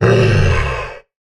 sounds / mob / camel / sit3.ogg
sit3.ogg